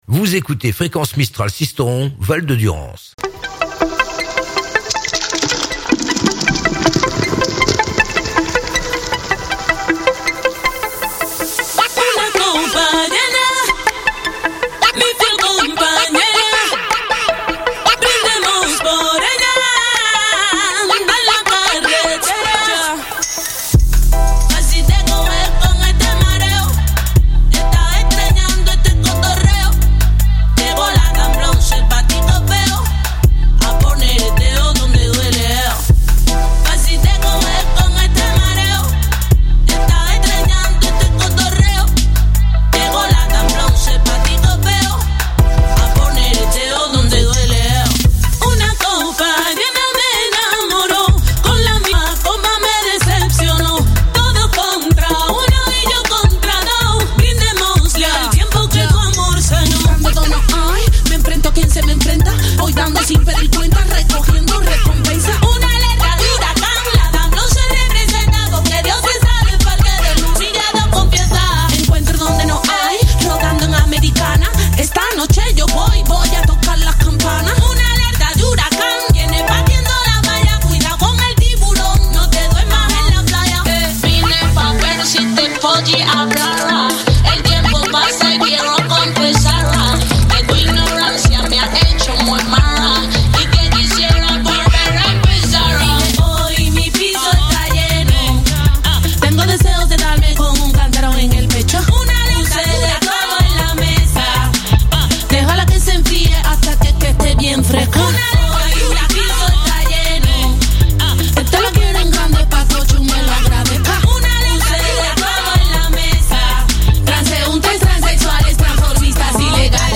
(65.59 Mo) Le rendez-vous incontournable tous les premiers mardi du mois sur le 99.2FM, ça continue en 2019 !
Radiothèque : une programmation exceptionnelle avec des tubes incontournables à écouter sur Fréquence Mistral, mais aussi des nouveautés et plus encore, le tout sans interruptions ou publicités.